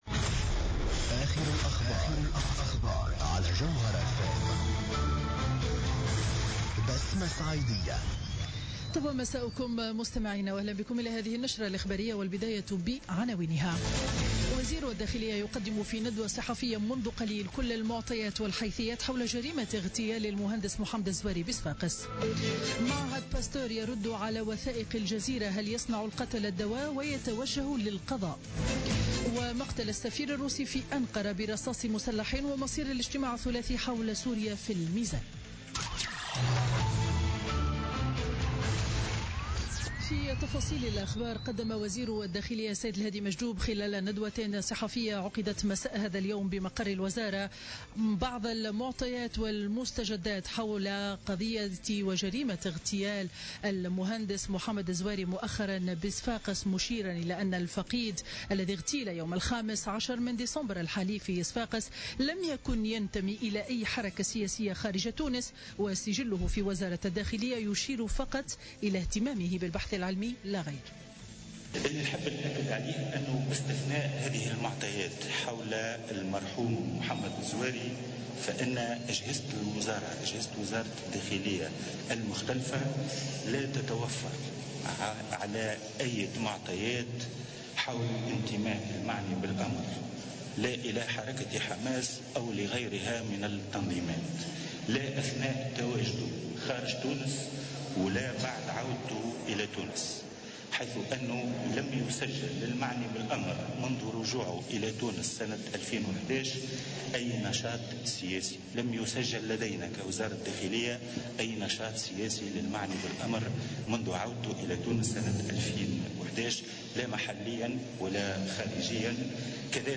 نشرة الأخبار ليوم الاثنين 19 ديسمبر 2016